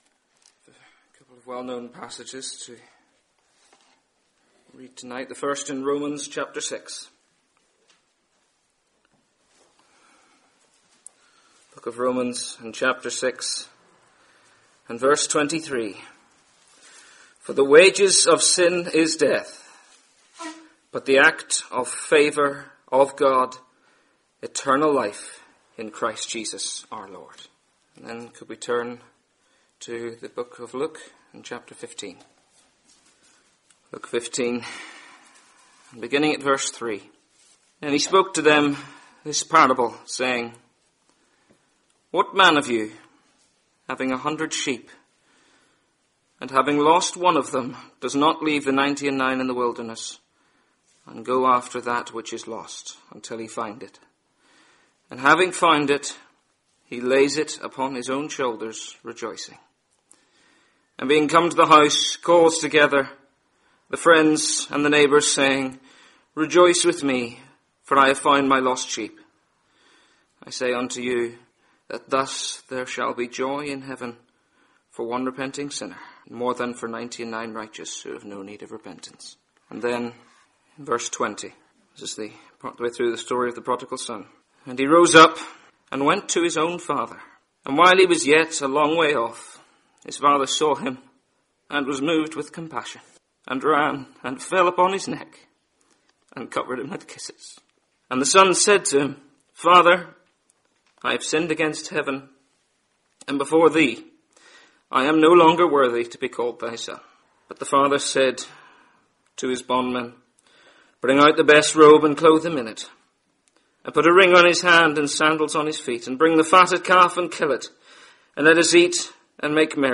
Listen to this Gospel preaching to hear how you can come into the blessing of knowing peace with God and the free gift of eternal life.